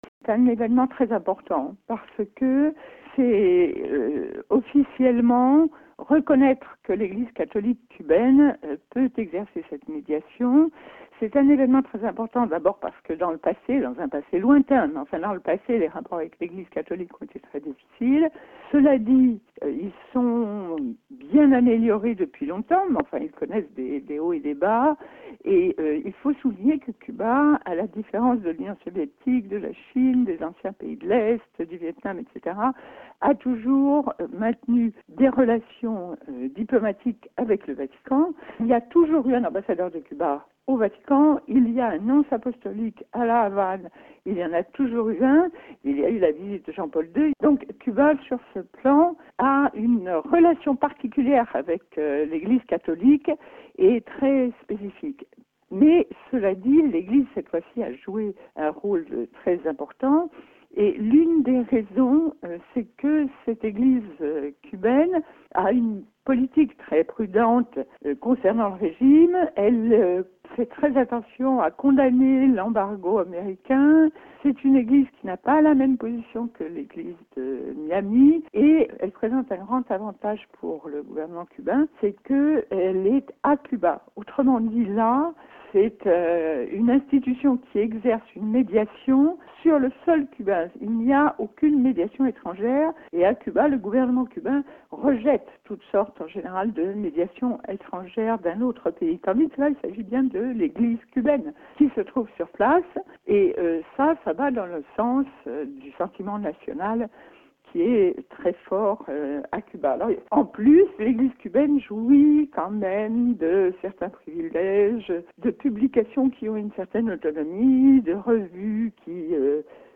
Elle est interrogée